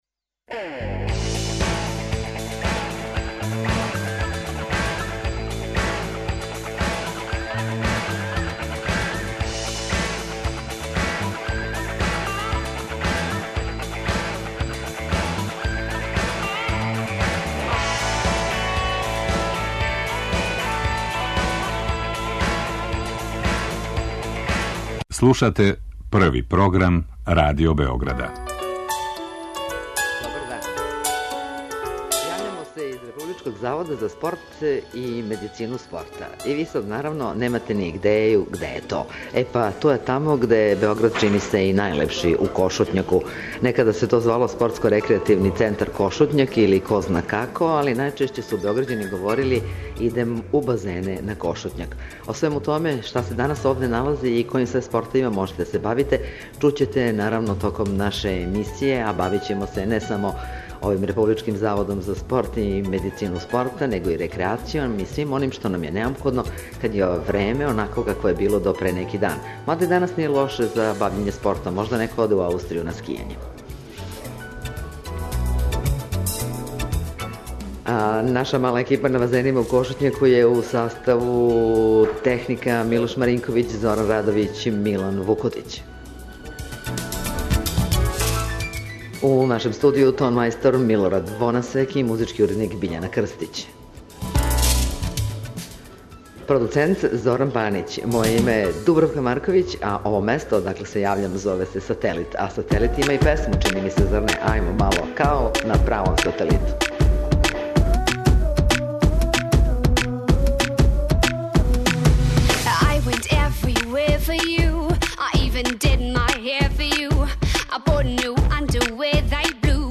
Дружимо се из Кошутњака, још једне београдске шуме, и пливамо. Наш студио биће базени спортског центра Кошутњак.